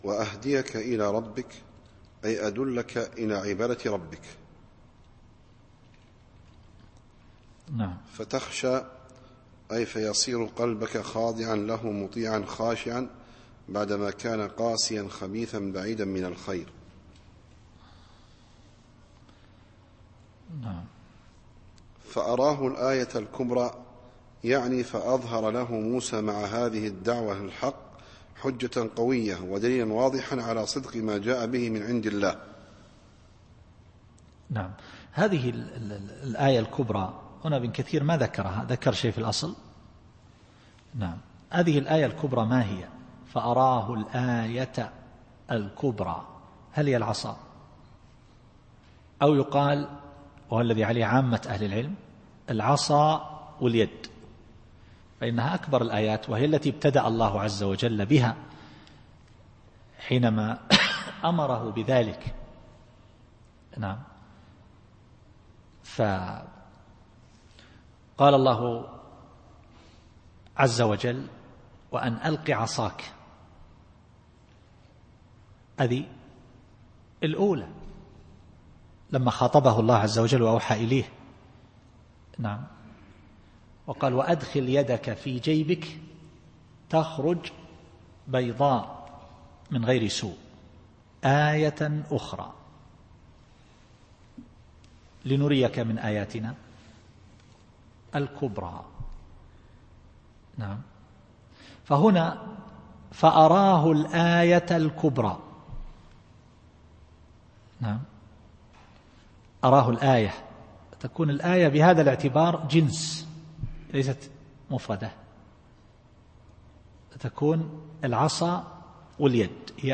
التفسير الصوتي [النازعات / 20]